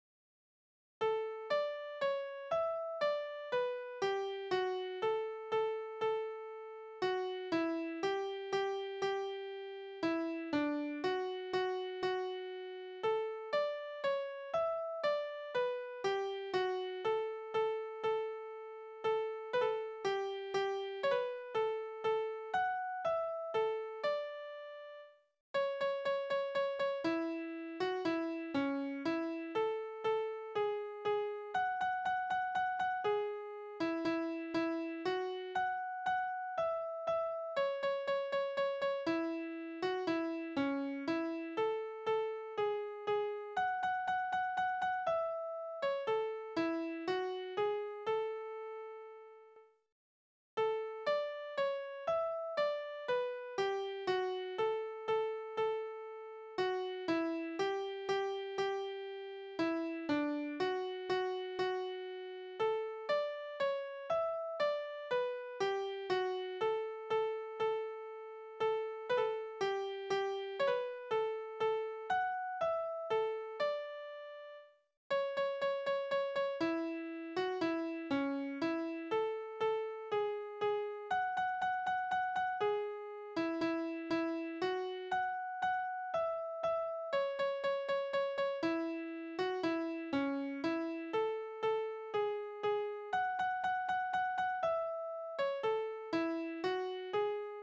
Liebeslieder:
Tonart: D-Dur, A-Dur
Taktart: 3/4
Tonumfang: Undezime
Besetzung: vokal